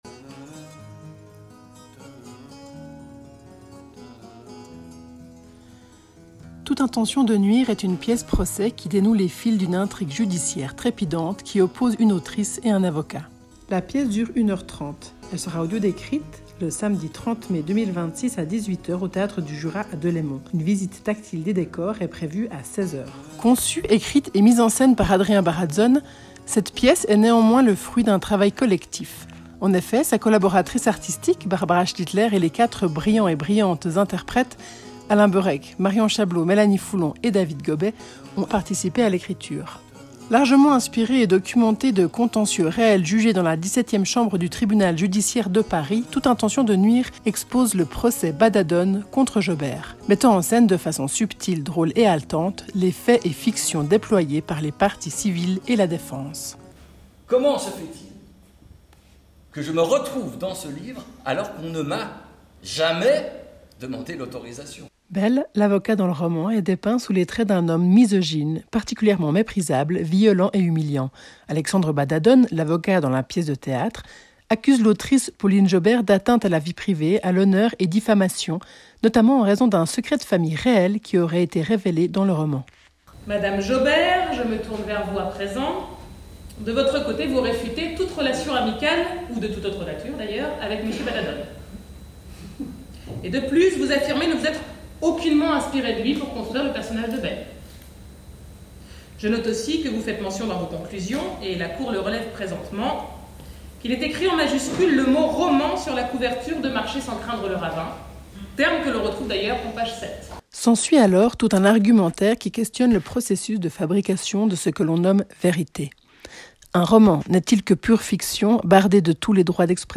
Théâtre
Audiodescription
Bande annonce